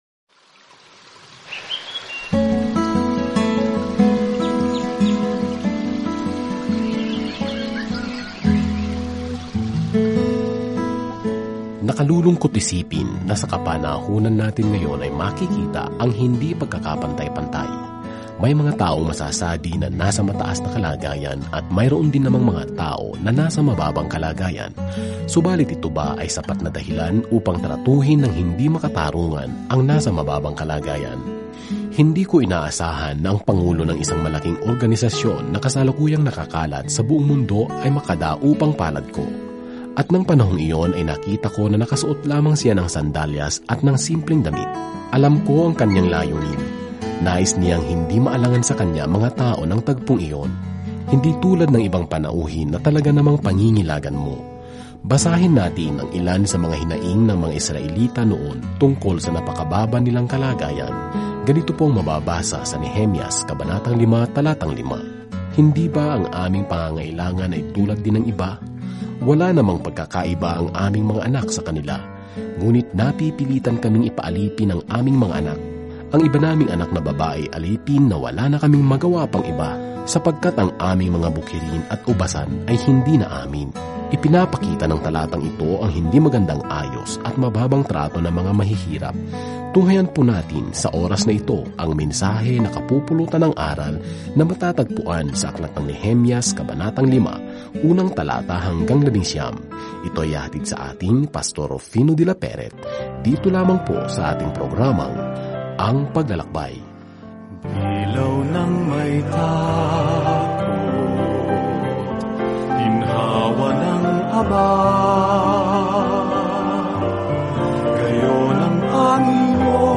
Banal na Kasulatan Nehemias 5 Araw 6 Umpisahan ang Gabay na Ito Araw 8 Tungkol sa Gabay na ito Nang bumalik ang Israel sa kanilang lupain, ang Jerusalem ay nasa masamang kalagayan; isang ordinaryong tao, si Nehemias, ang muling nagtayo ng pader sa palibot ng lungsod sa huling aklat na ito ng Kasaysayan. Araw-araw na paglalakbay sa Nehemias habang nakikinig ka sa audio study at nagbabasa ng mga piling talata mula sa salita ng Diyos.